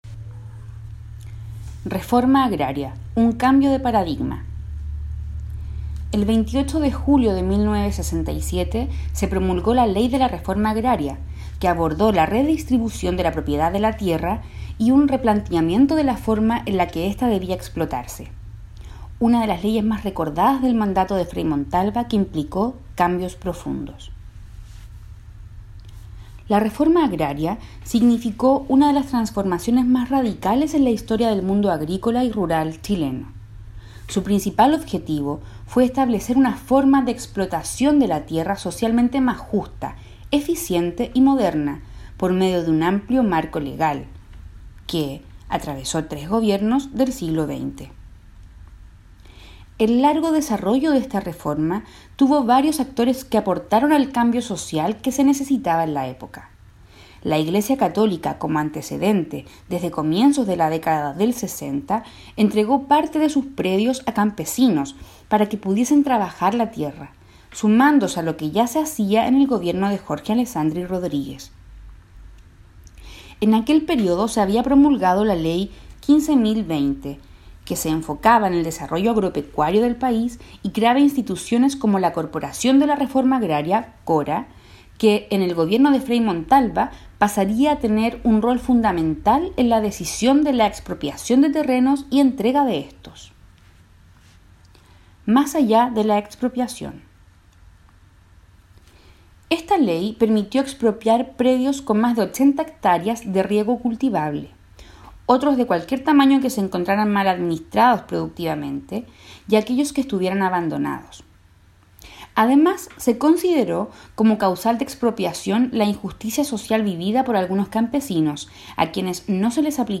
Audio artículo